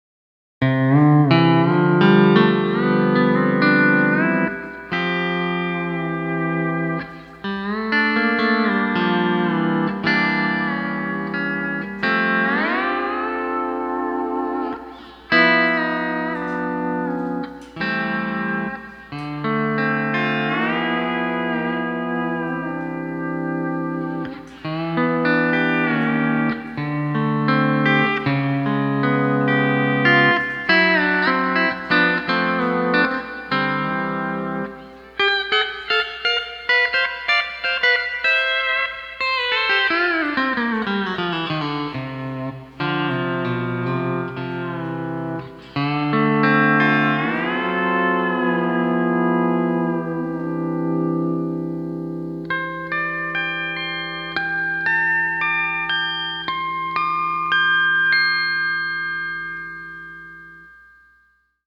E9th Copedant Example - Splits, 9 & 10 Lower, 7 Raise : The Steel Guitar Forum
Here's an example of the 9th string half tone lower, 10th string full tone lower, 5th string split tuning and 7th string whole tone raise all smashed together.